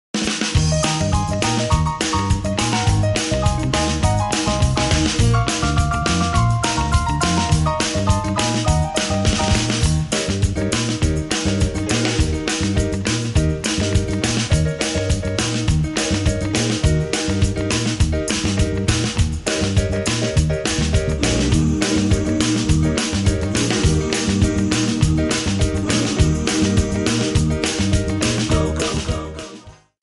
Eb
MPEG 1 Layer 3 (Stereo)
Backing track Karaoke
Pop, Oldies, 1960s